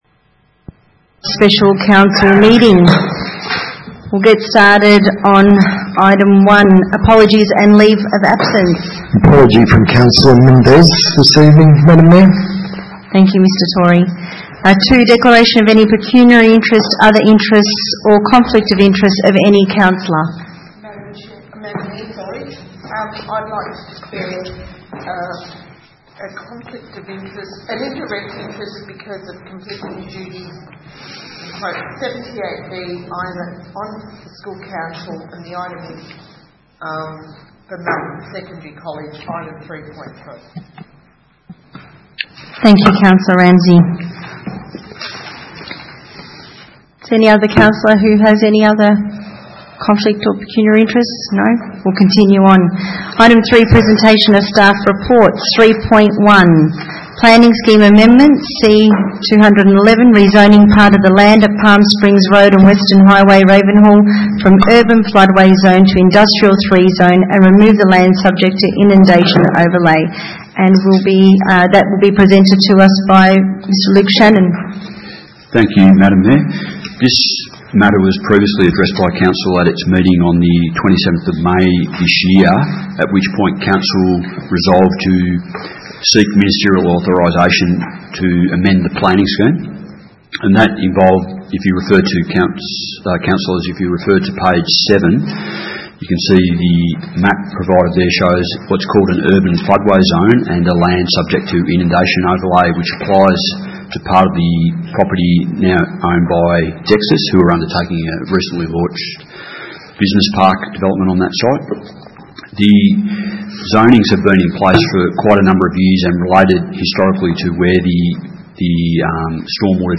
Special Meeting 16 December 2019
Melton Chambers, 232 High Street, Melton, 3337 View Map